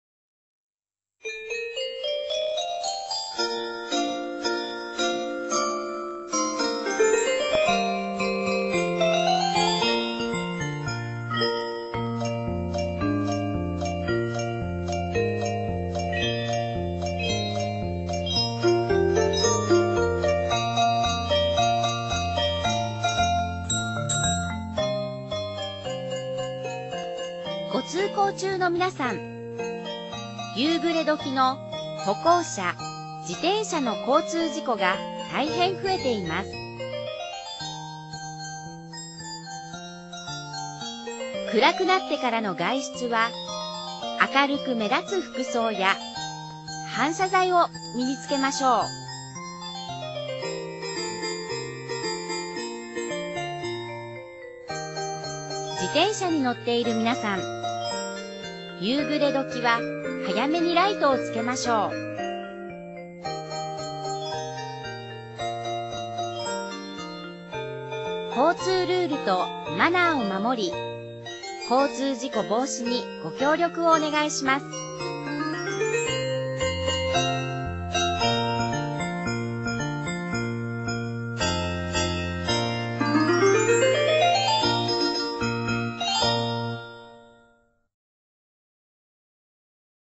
内容は、「ピーポくんのうた」オルゴールバージョンをBGMに、歩行者・自転車など、テーマ別に大切なルールやマナーを呼びかけています。